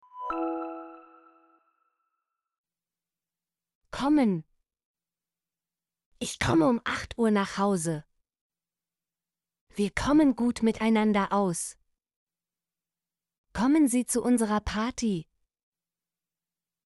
kommen - Example Sentences & Pronunciation, German Frequency List